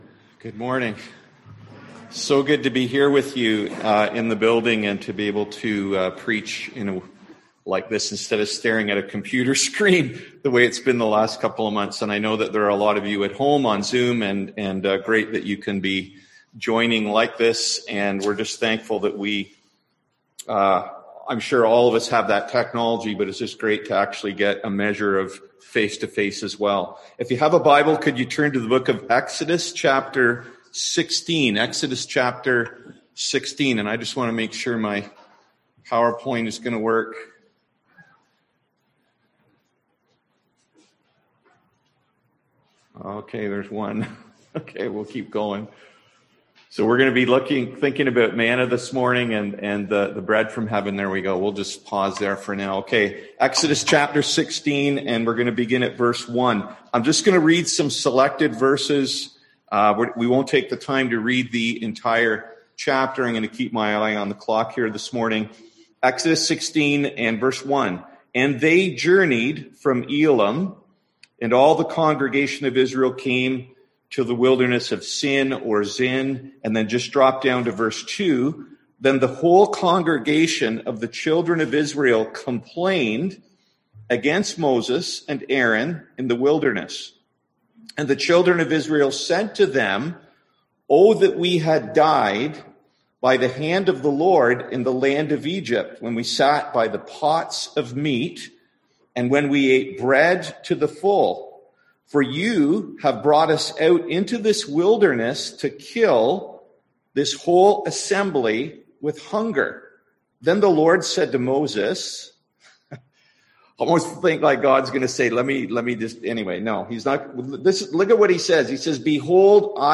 Service Type: Sunday AM Topics: God's Provision